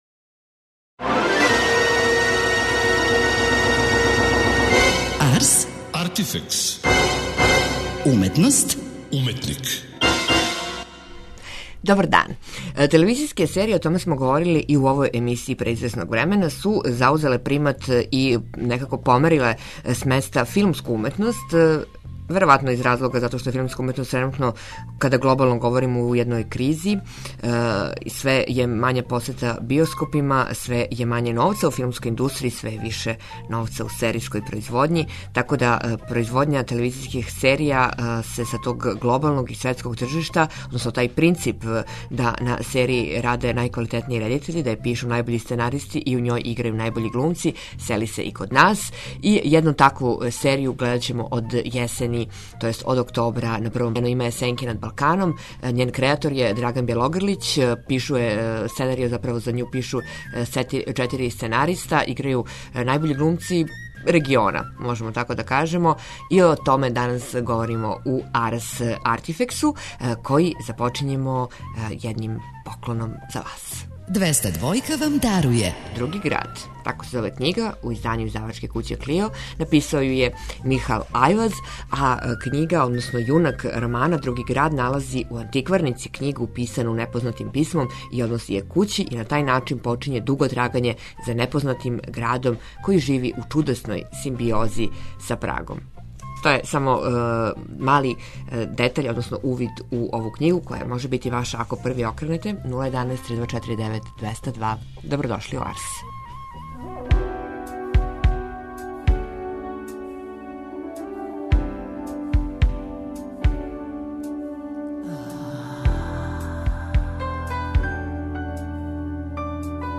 О "Сенкама над Балканом" говоре аутор пројекта Драган Бјелогрлић , један од сценариста, писац Владимир Кецмановић и глумац Горан Богдан .